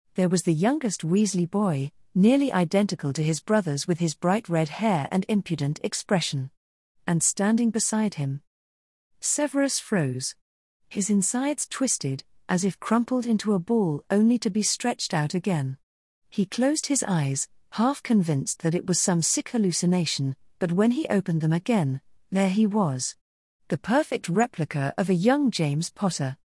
I'm not sure how many readers will be interested in audiobook versions, but I figured it would be nice to have the option.
As far as AI voices go, this is one of the best I've found.
That's a very good AI voice.